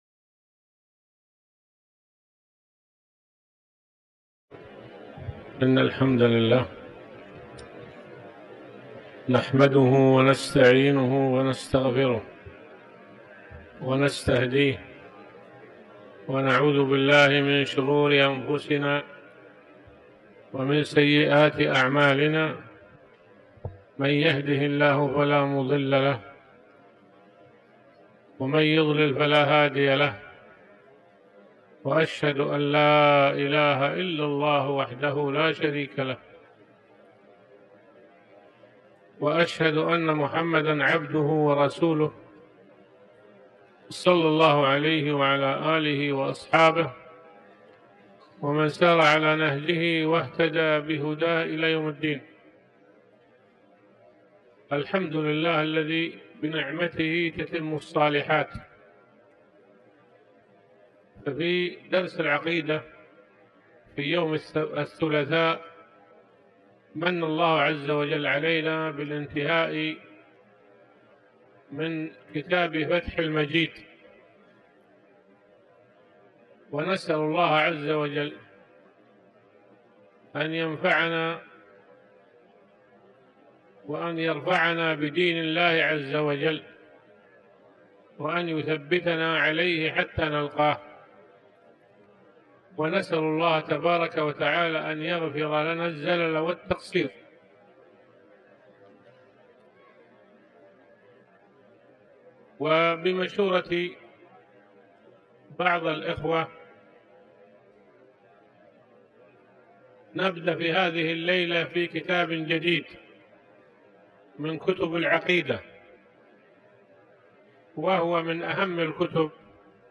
تاريخ النشر ٥ رجب ١٤٤٠ هـ المكان: المسجد الحرام الشيخ